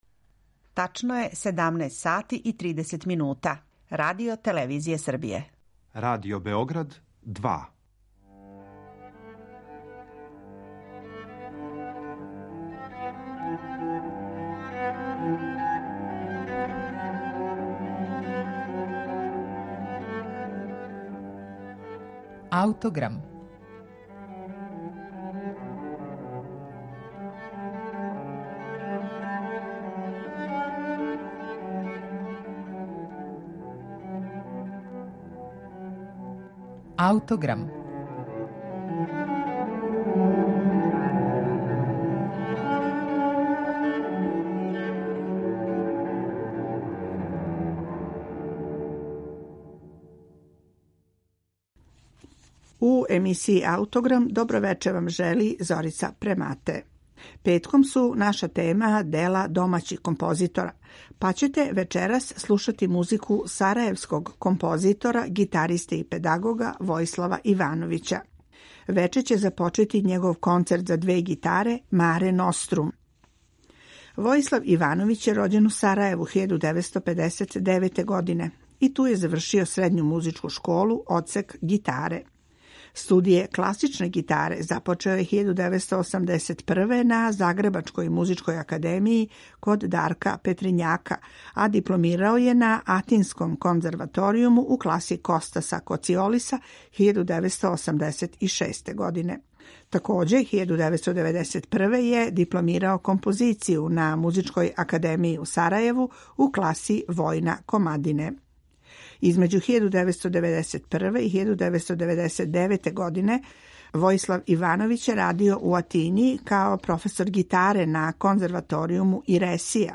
Слушаћете концертанту музику за гитару
концерт за две гитаре
Снимак је остварен 2015. године, на концерту